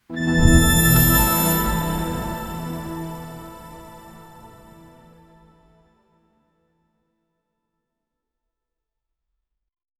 Подходящее старшему поколению. 0:10 A crisp, rich, elegant and modern layered device startup sound for a premium product. 0:10 Мелодия запуска прибора, минималистичная без резких звуков.
a-crisp-rich-elegant-and-x3kwkhr4.wav